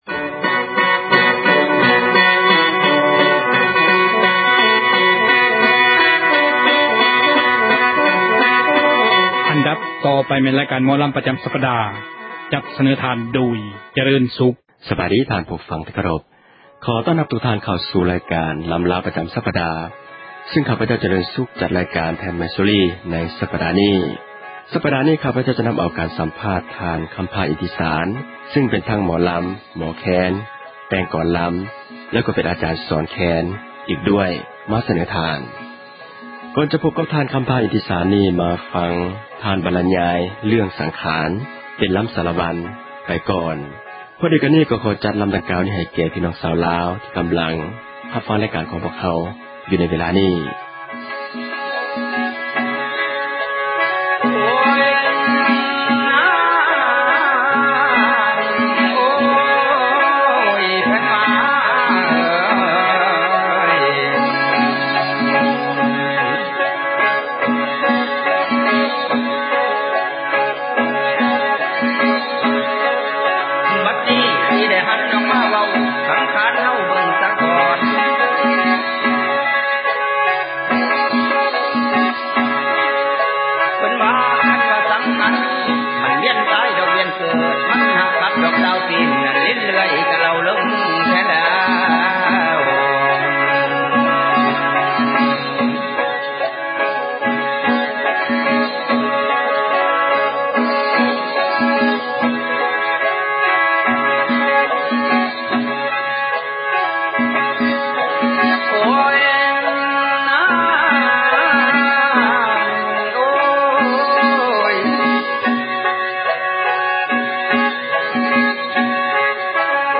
ພ້ອມກັບ ລໍາສາຣະວັນ ”ສັງຂານ”.